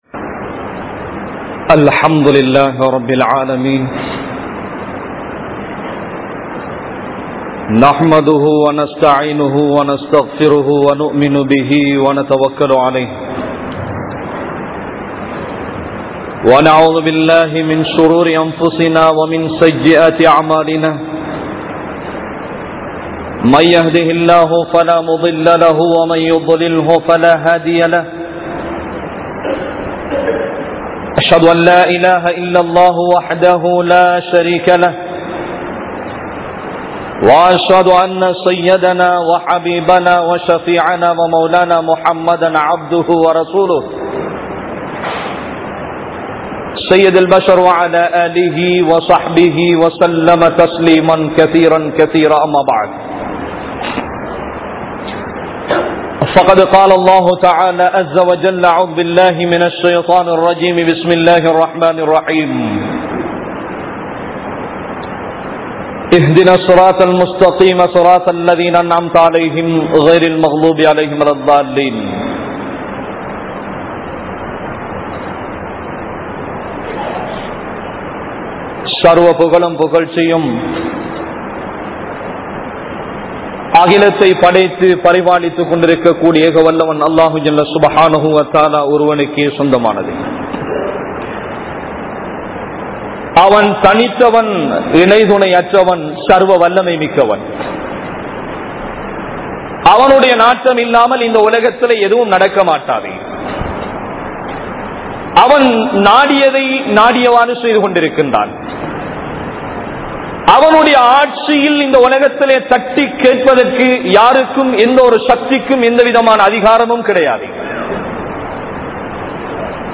Pari Poahum Masjidhhal (பறி போகும் மஸ்ஜித்கள்) | Audio Bayans | All Ceylon Muslim Youth Community | Addalaichenai